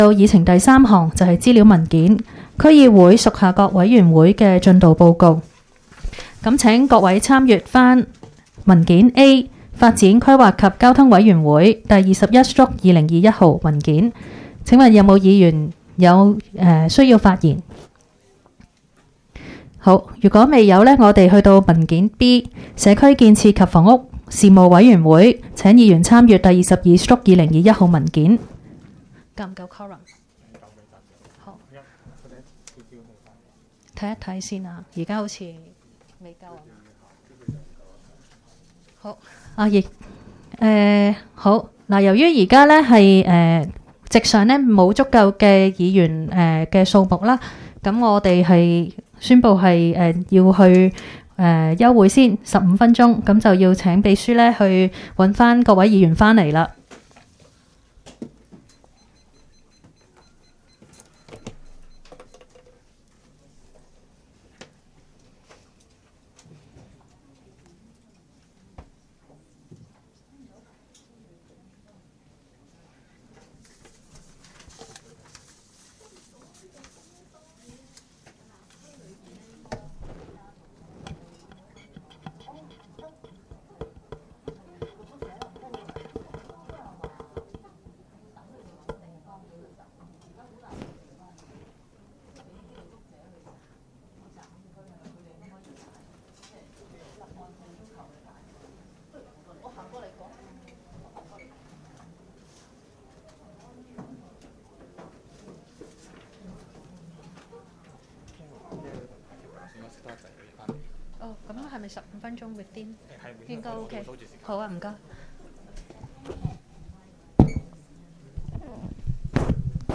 区议会大会的录音记录